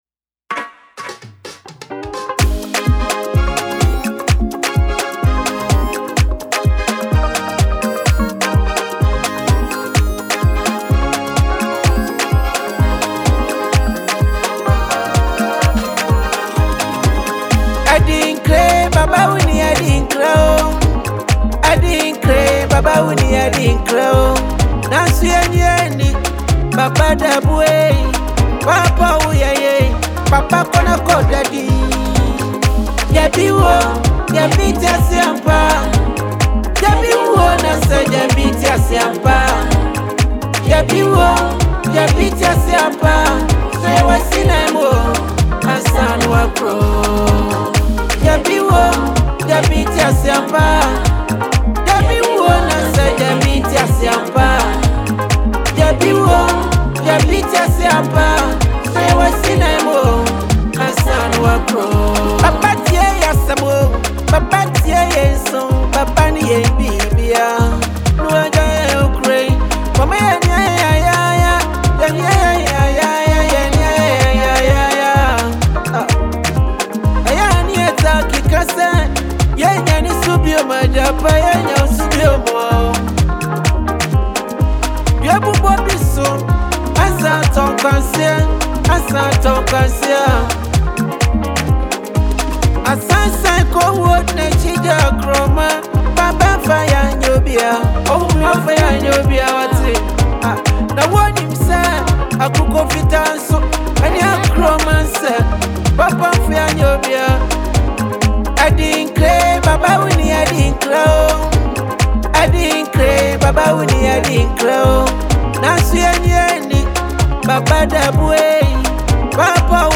emotional and heartfelt farewell song
powerful vocals